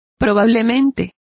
Complete with pronunciation of the translation of likely.